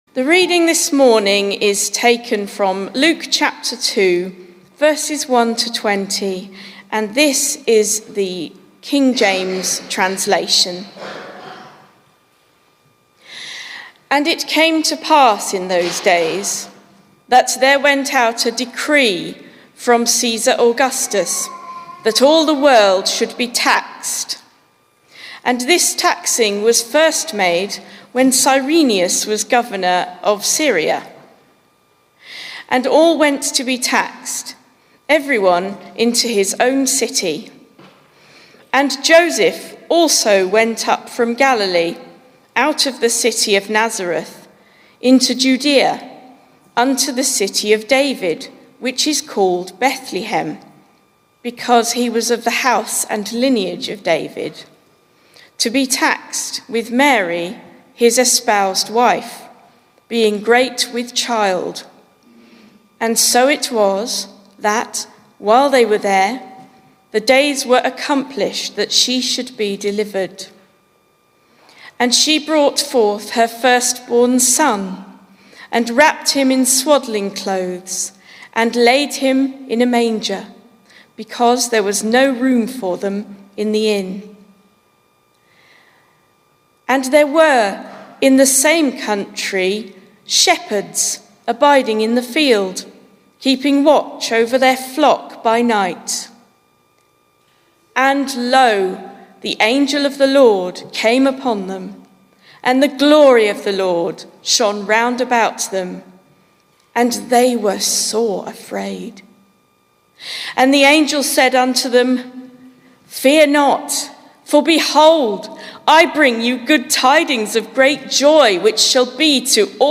My reading of Luke 2:1-20, the story of the birth of Jesus, at Church on Christmas morning
The Christmas morning Gospel reading taken from the King James version of the Bible